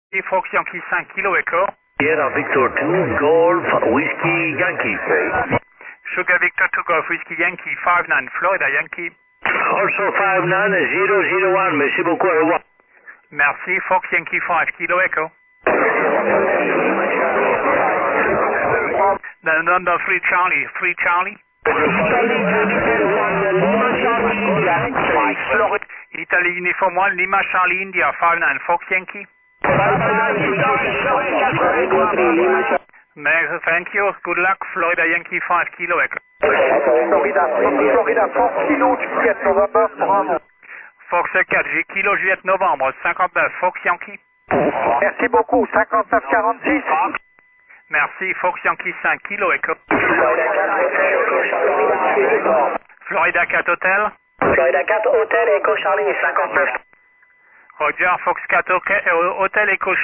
3. QRM Management: Review how your signals survived adjacent and powerful signals during “pile-up” conditions.